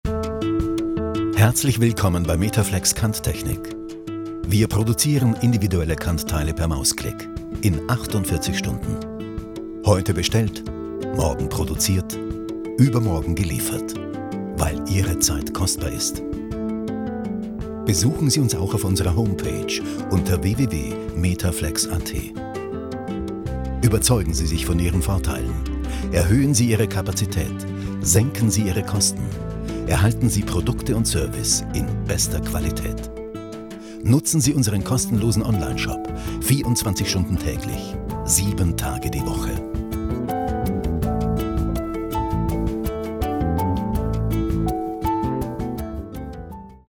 Hörprobe: Professionelle Telefonansagen für Metaflex Kanttechnik
Diese wurde für die Firma Metaflex Kanttechnik vor einigen Tagen in den Innenhofstudios in Wien aufgenommen. Die Stimme ist österreichweit wohl bekannt - Herr Otto Klemens (Sprecher der Universum Dokumentationen), hat sich bereit erklärt für Metaflex seine Stimme bereit zu stellen.